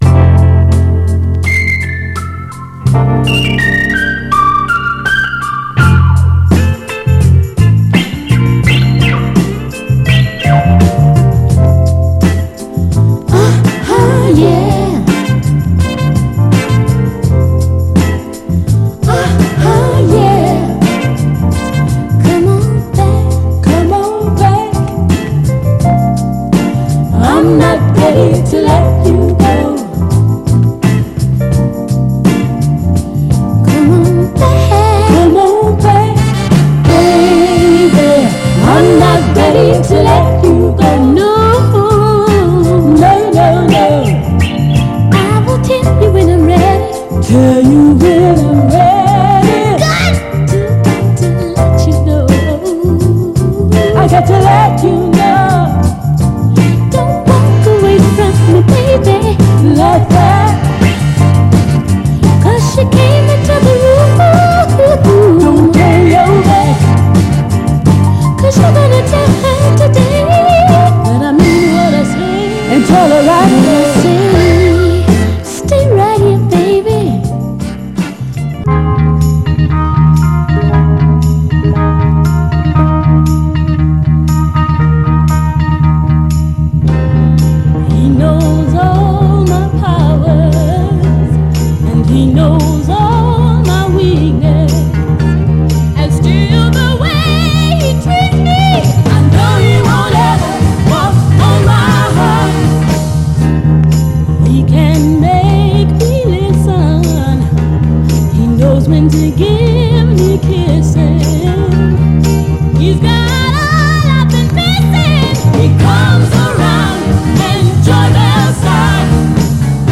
ノーザン・ソウル・ファンにも支持されるメンフィス・トゥ・シカゴなファンキー・ゴスペル・ダンサーで両面良いです。
ただし音への影響は少なく、見た目の割にプレイ概ね良好です。
※試聴音源は実際にお送りする商品から録音したものです※